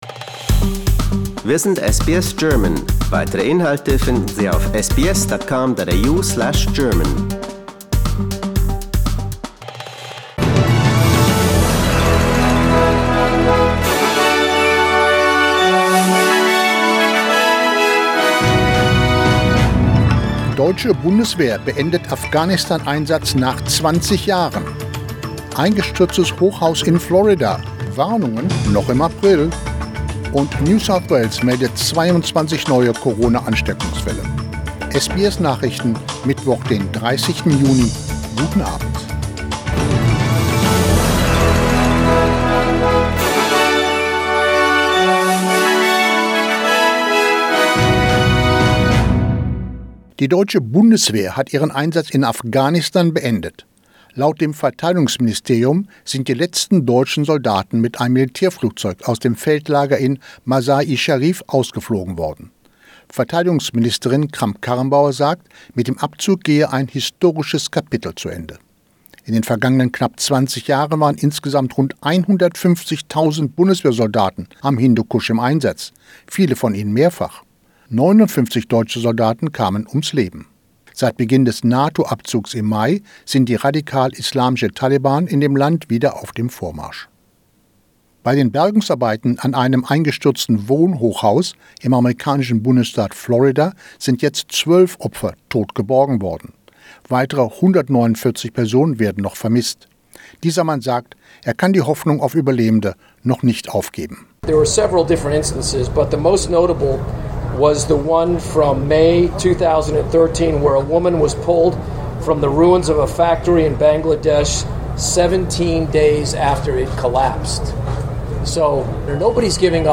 SBS Nachrichten, Mittwoch 30.06.21